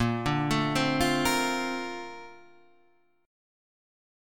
A# Major 9th